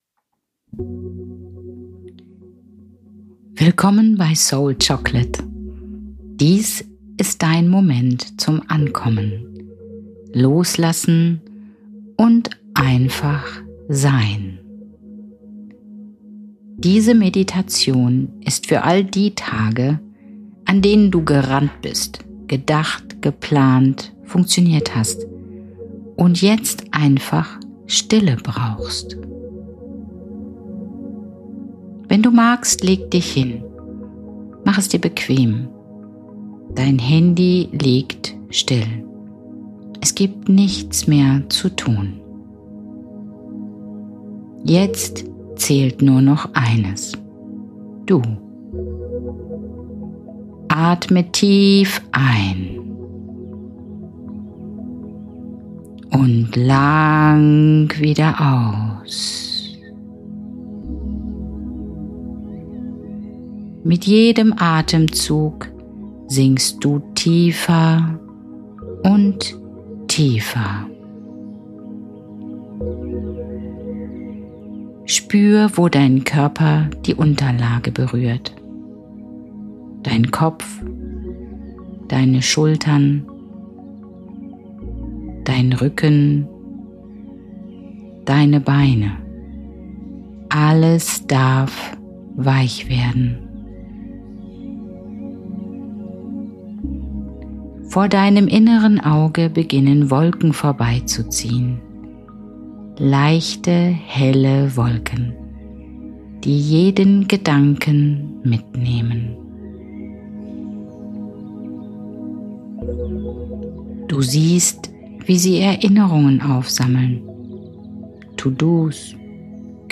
Beschreibung vor 5 Monaten Diese Soul-Chocolate-Meditation begleitet dich in einen tiefen Zustand der Ruhe. Mit bewusster Atmung, achtsamer Muskelentspannung und sanften Bildern gleitest du Schritt für Schritt aus dem Denken in das Fühlen.
führt dich mit ruhiger Stimme durch den Abend, bis du tief entspannst, loslässt und sanft in den Schlaf sinkst.